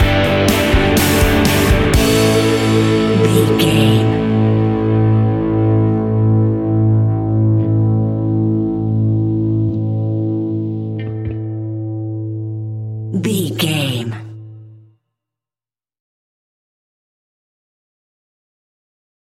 Uplifting
Mixolydian
hard rock
blues rock
Rock Bass
heavy drums
distorted guitars
hammond organ